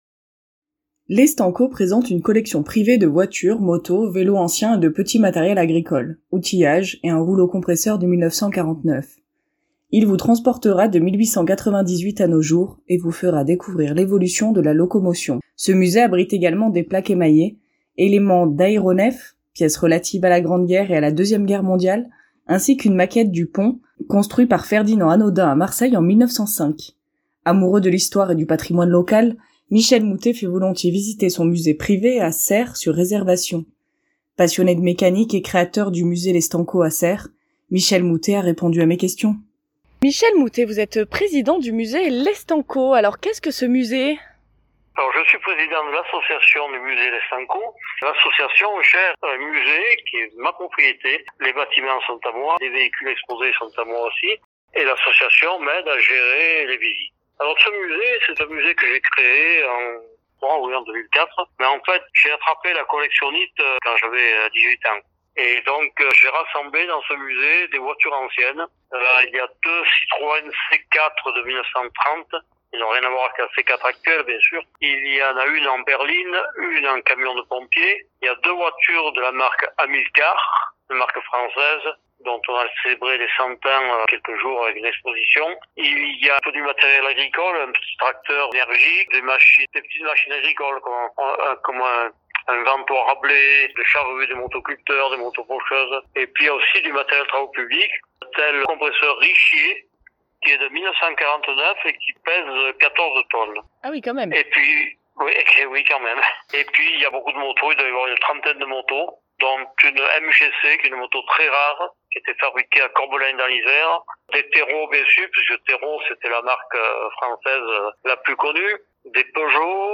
répond aux questions